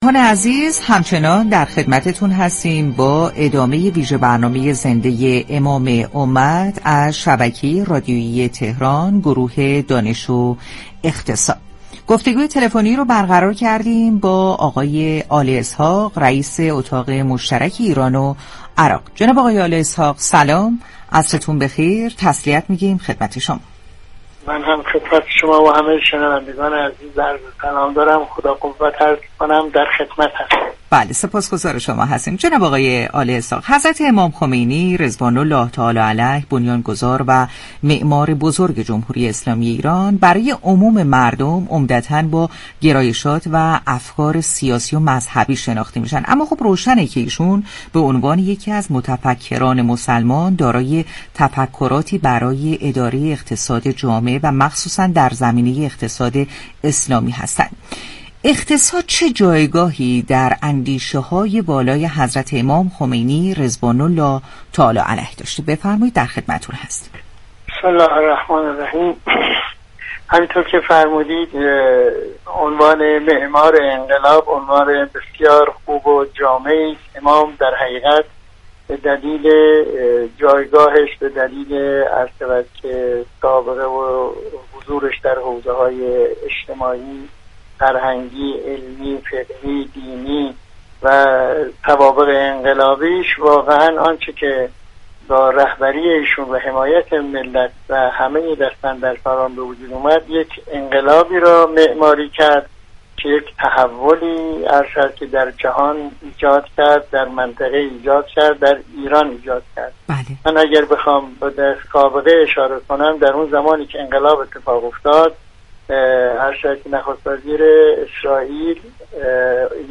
به گزارش پایگاه اطلاع رسانی رادیو تهران، یحیی آل اسحاق رئیس اتاق بازرگانی مشترك ایران و عراق در گفت و گو با ویژه برنامه «امام امت» اظهار داشت: علی‌رغم اینكه در حوزه‌های مختلف رشد كردیم در حوزه اقتصاد متناسب با توانایی‌هایمان موفقیت آنچنانی نداشتیم.